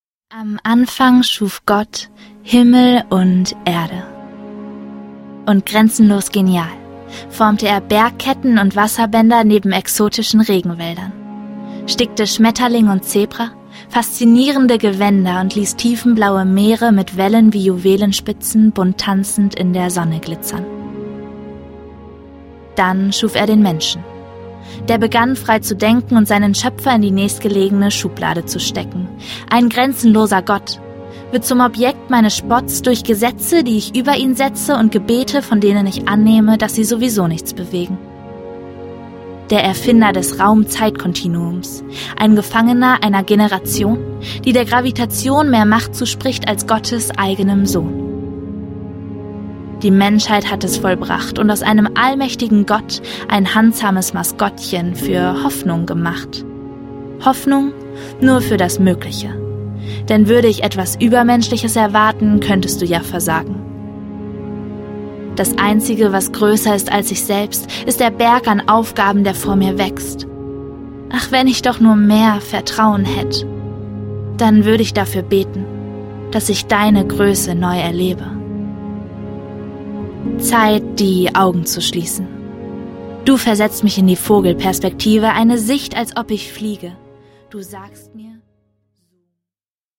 Zoom raus (MP3-Hörbuch - Download)